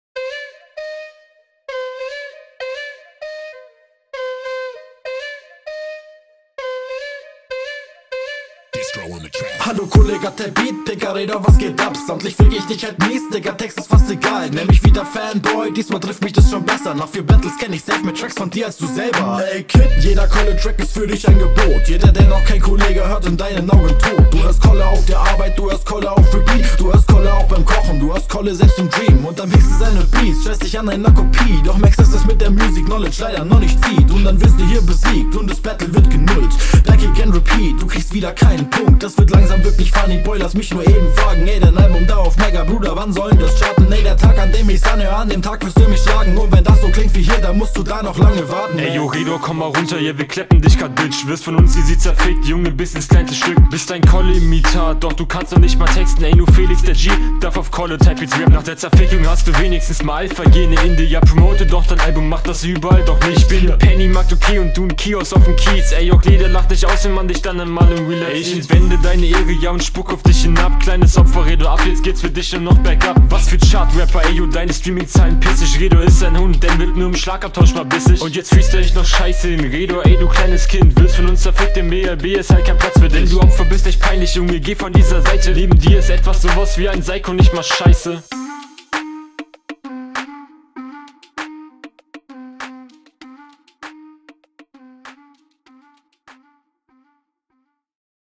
Vocal sitzt bissel zu sehr im Beat, sonst bist du halt besser punkt